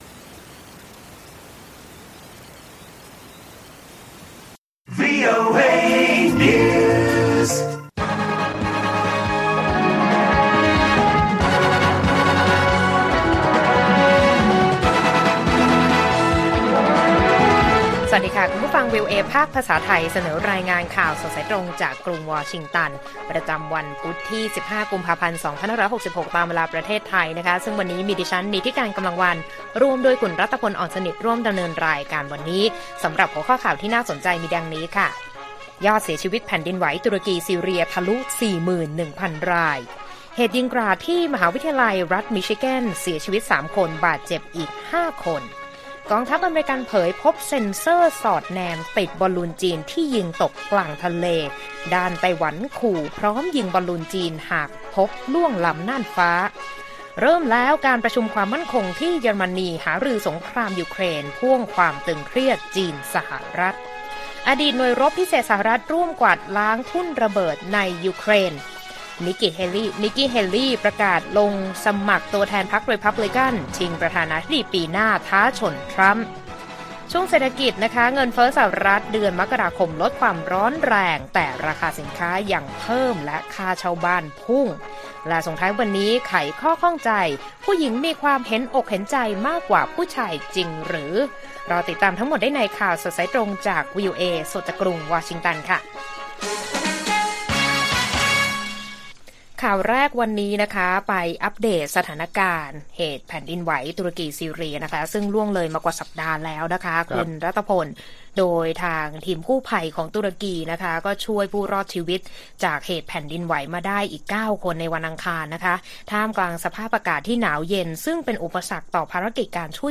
ข่าวสดสายตรงจากวีโอเอ ไทย 15 กุมภาพันธ์ 2566